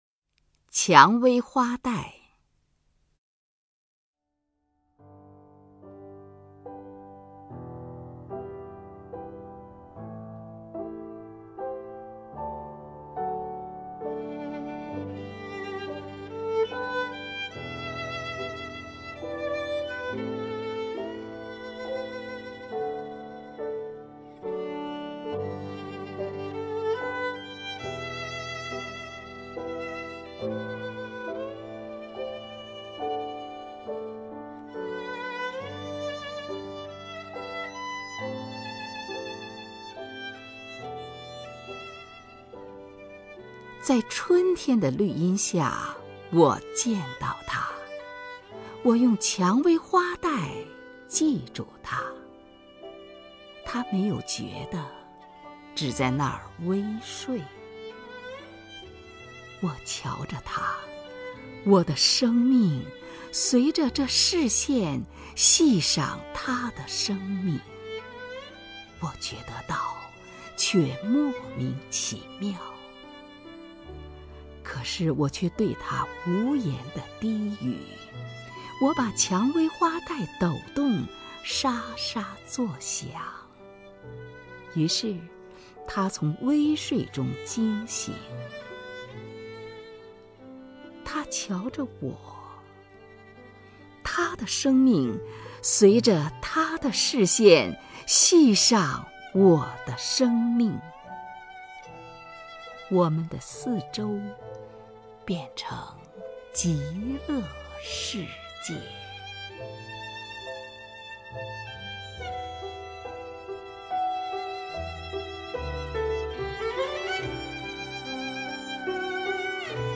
首页 视听 名家朗诵欣赏 虹云
虹云朗诵：《蔷薇花带》(（德）克洛普斯托克)